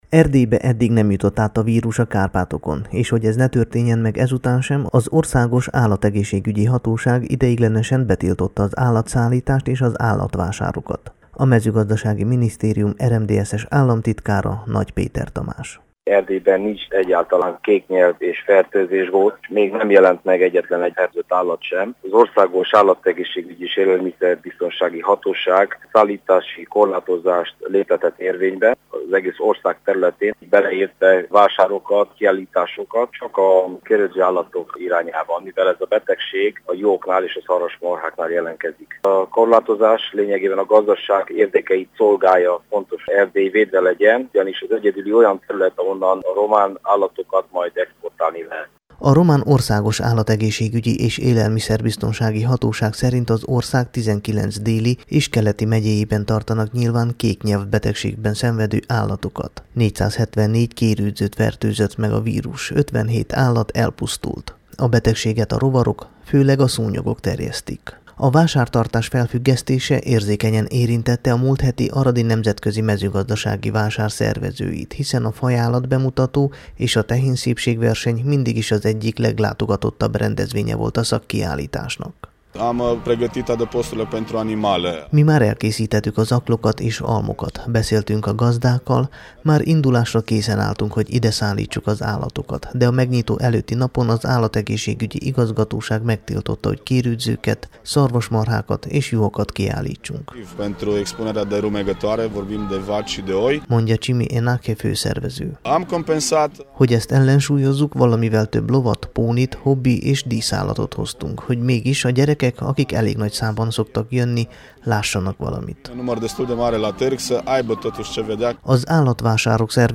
Nagy Péter Tamás államtitkár Nagy Péter Tamás államtitkár
Hallgassa meg Nagy Péter Tamás nyilatkozatát!